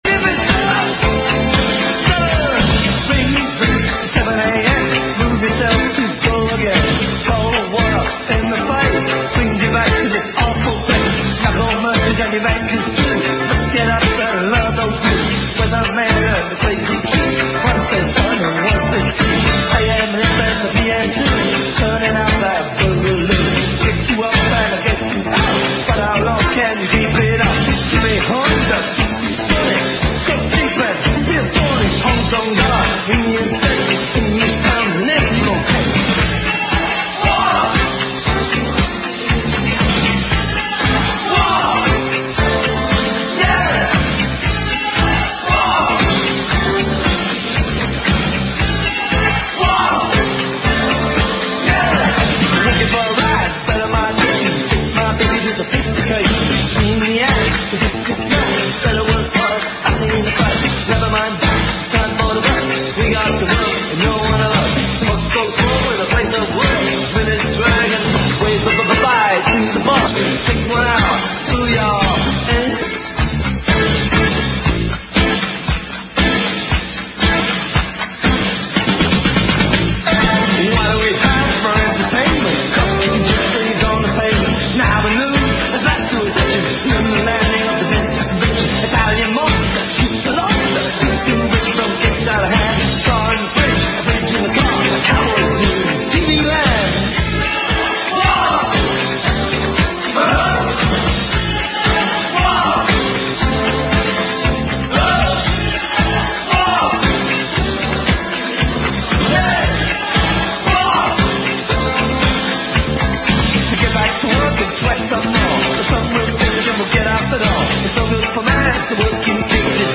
In questa puntata è stata tracciata una geografia degli spazi autogetiti a Bologna e delle realtà a rischio di sgombero. Si è parlato anche delle politiche dell'amministrazione comunale e del loro rapporto col territorio. Interviste ad attivisti di Crash, Atlantide, XM24 e TPO.